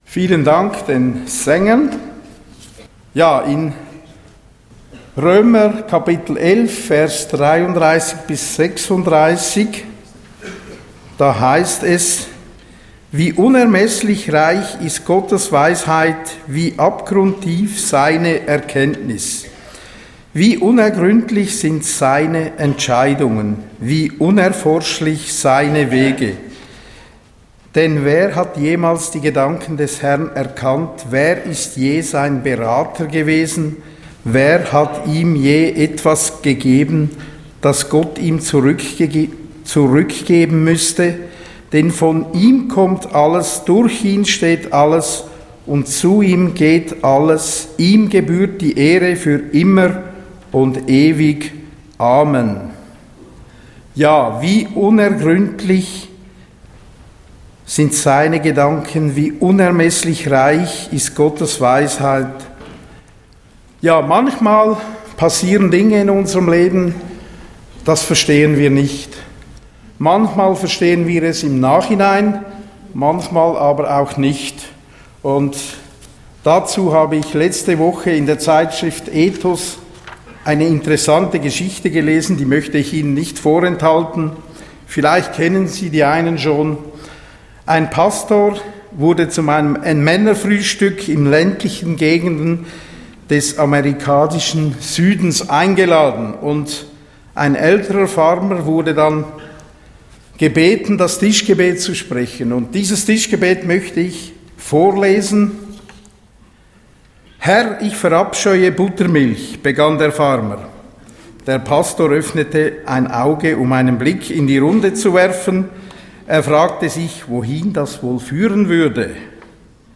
Einleitungen Gottesdienst